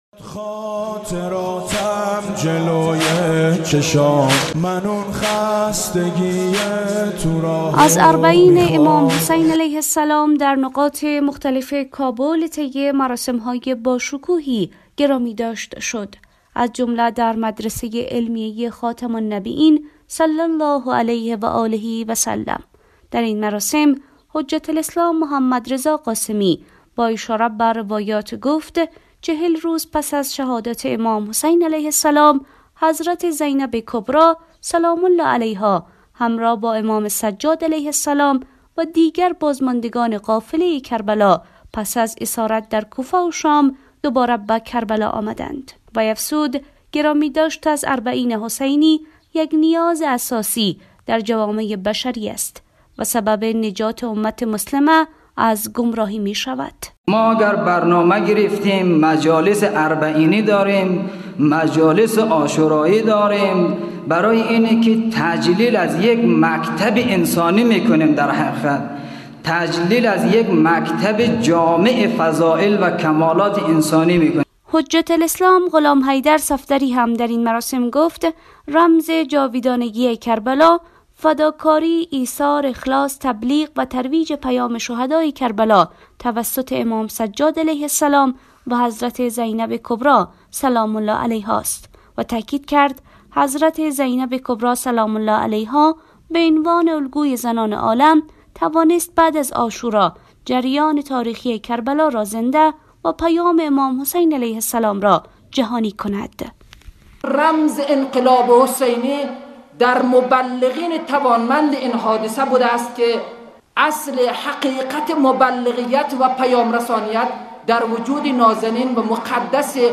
مراسم گرامیداشت اربعین حسینی در حوزه علمیه خاتم النبیین(ص) کابل برگزار شد.
گزارش